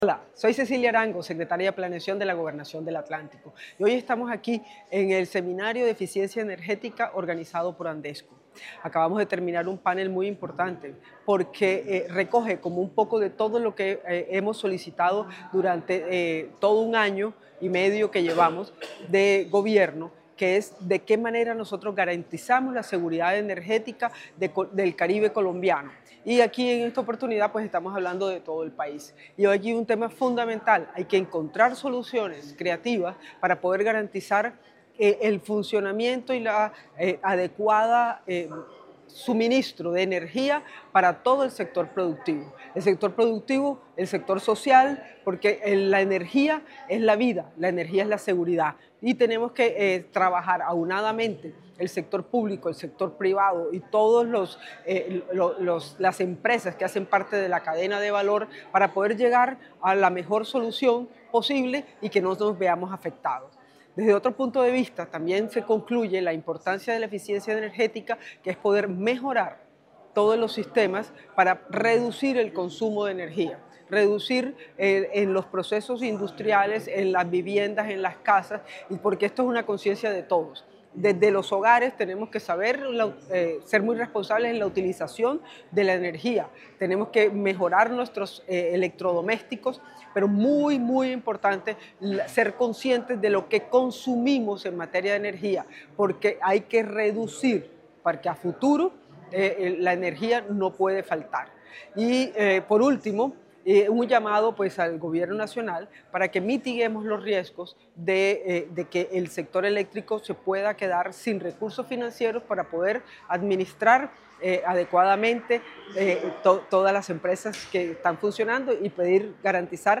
Esta postura fue resaltada durante el XIV Seminario de Eficiencia Energética, organizado por Andesco, donde la secretaria de Planeación del departamento, Cecilia Arango, participó como panelista en el cierre del evento.
Audio-Cecilia-Arango-secretaria-de-Planeacion-del-Atlantico-2.mp3